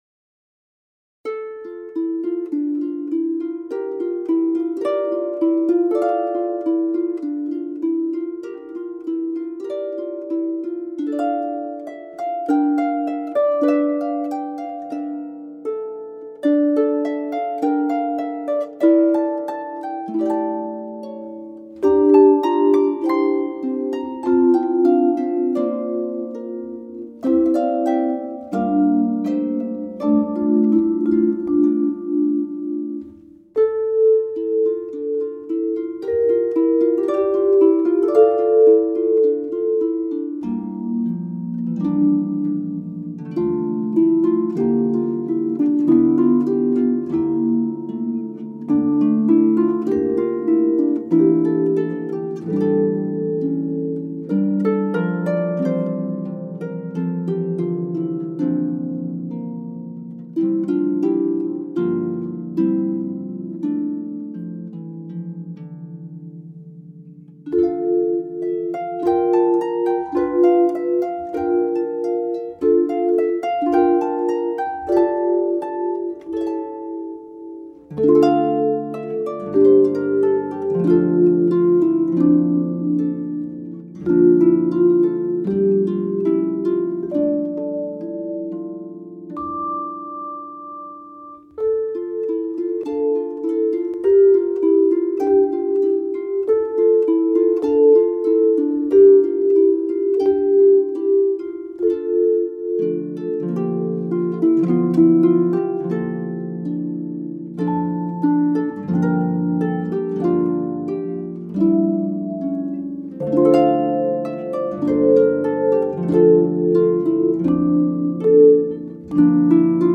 on vibraphone.